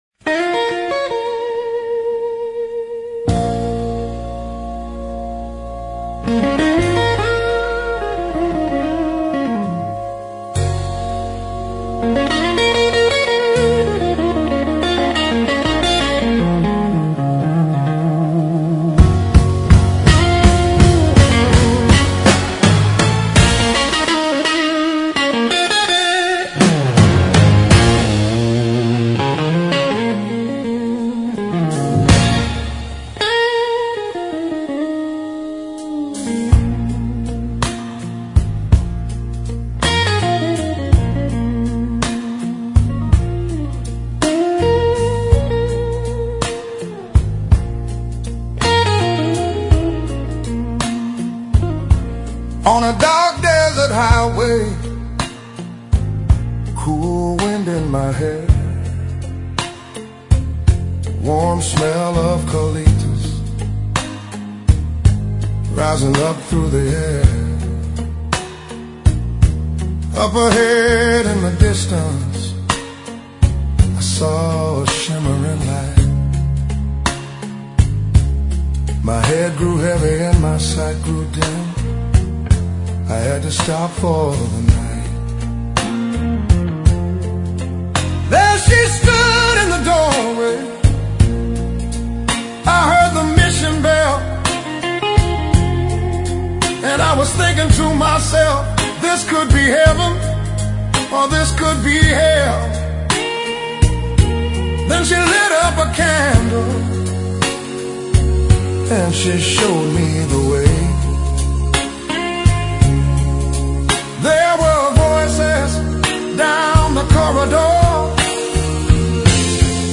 Blues Songs